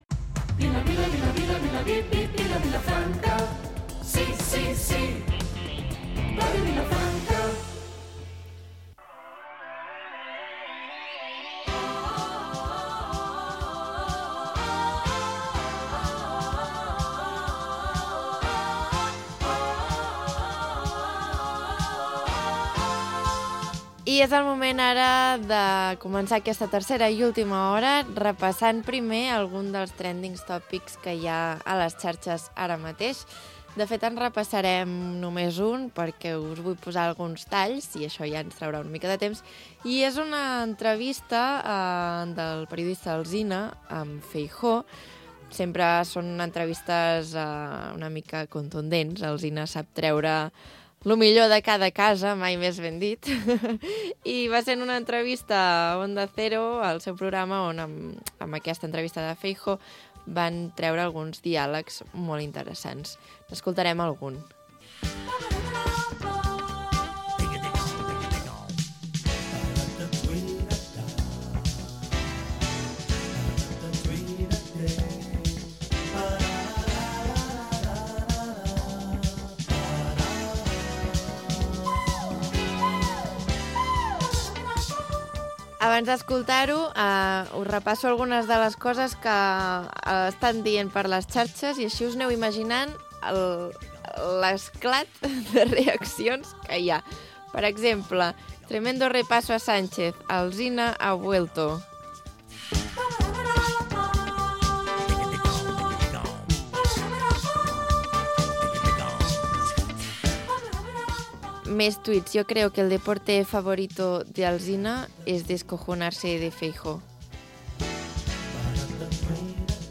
Magazín diari d'estiu